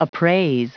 Prononciation du mot : appraise
appraise.wav